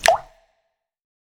TC3Snare22.wav